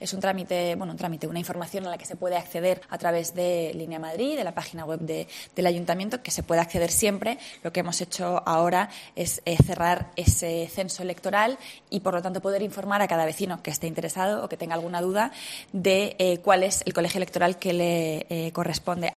Rita Maestre sobre el censo electoral de cara a las elecciones generales del 28-A